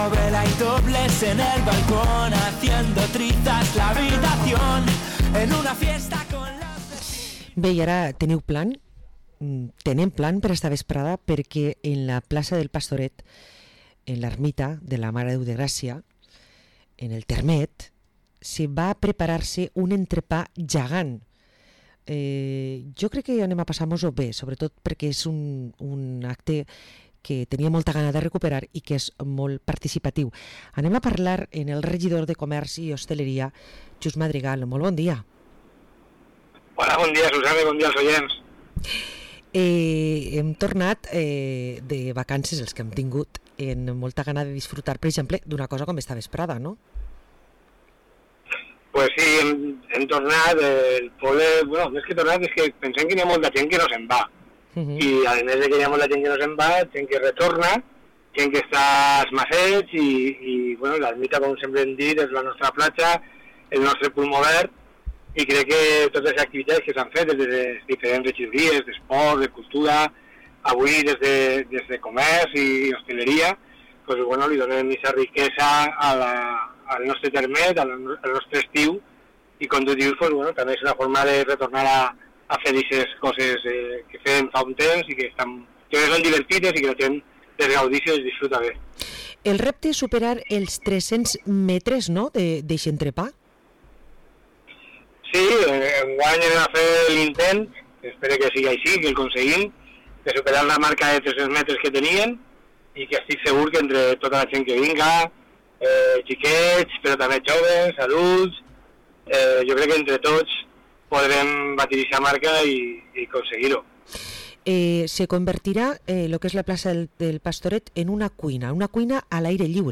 Parlem amb el regidor Xus Madrigal